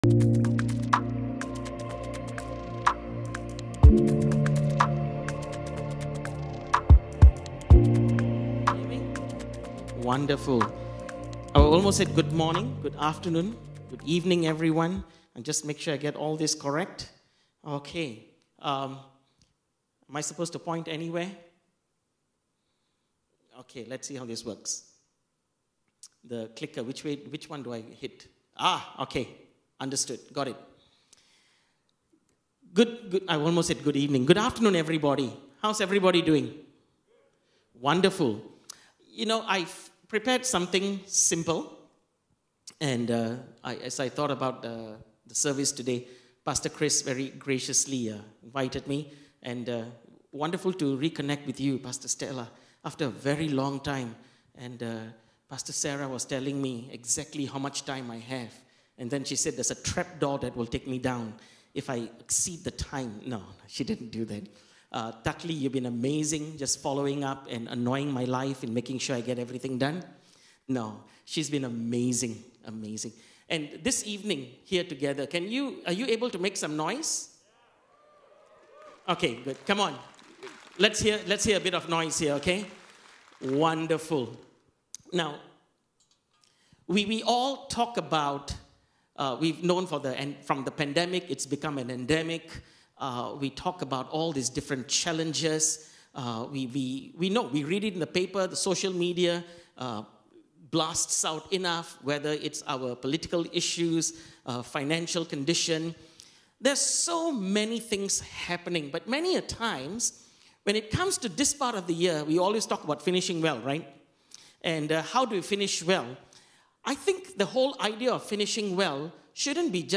All Sermons Finishing Well- Resilience: Why?